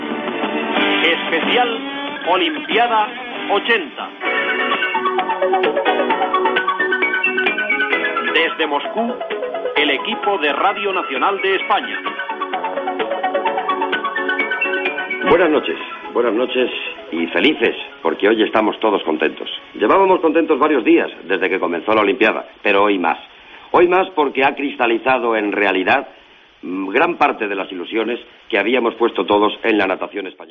Careta del programa i presentació del programa des de Moscou.
Esportiu